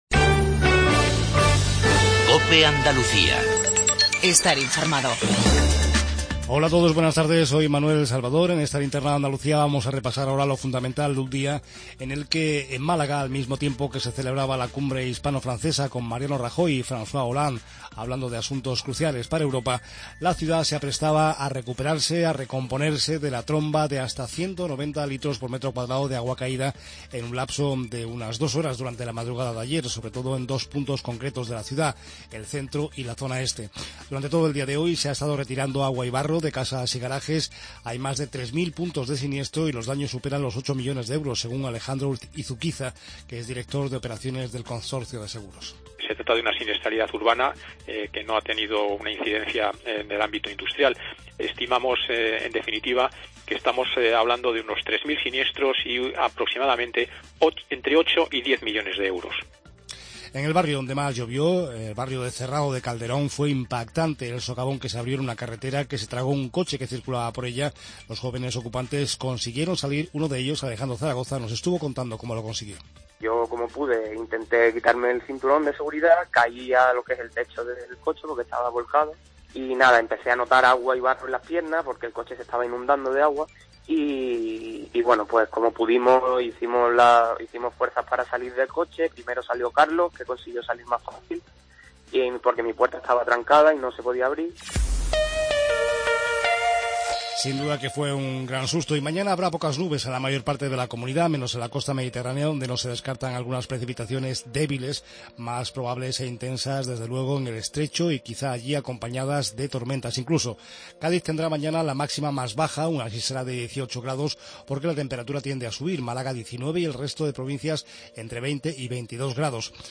INFORMATIVO REGIONAL TARDE